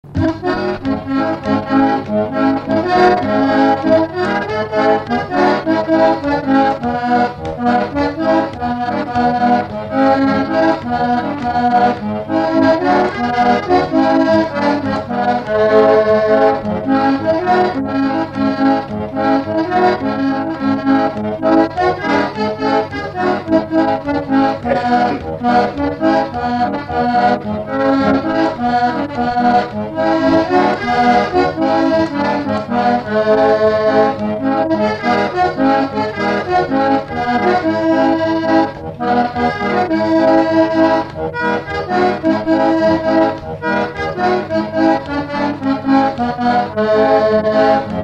Chanson sud américaine
Instrumental
danse : séga
Pièce musicale inédite